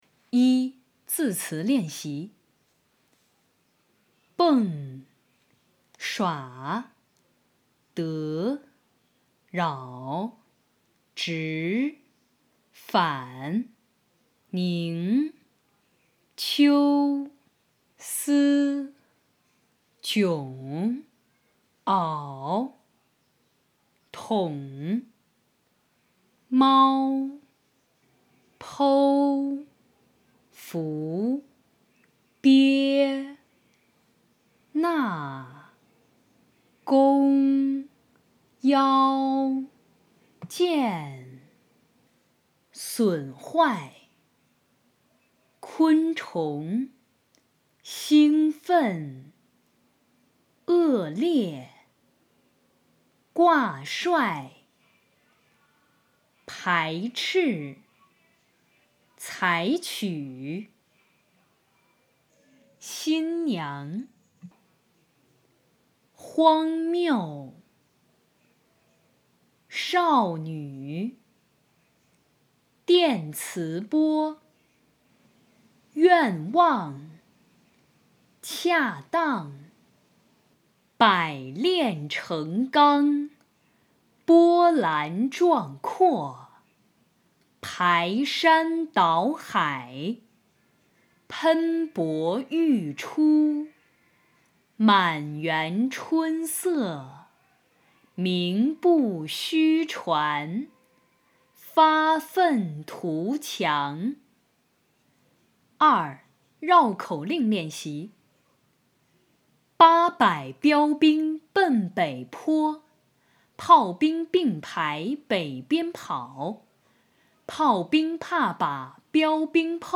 领读课件